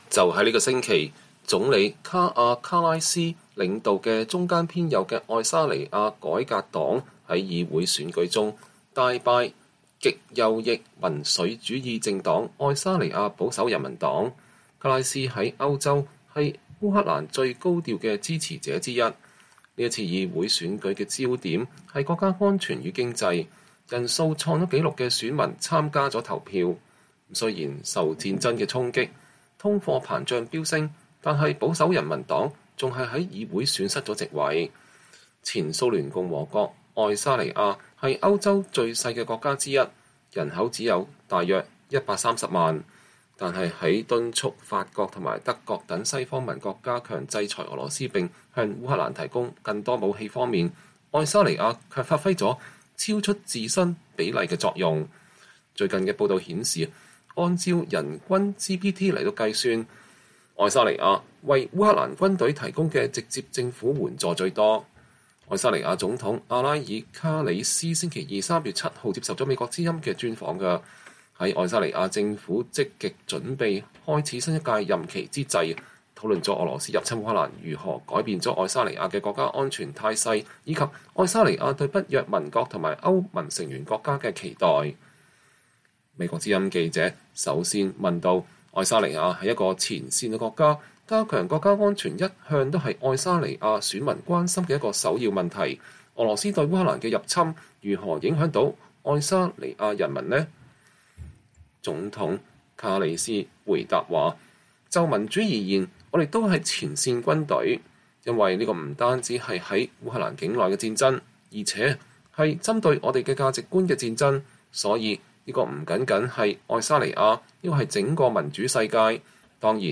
VOA專訪愛沙尼亞總統：俄羅斯入侵烏克蘭是對跨大西洋價值觀與民主本身的攻擊